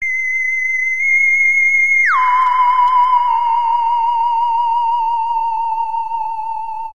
загадочные , космические , без слов , мистические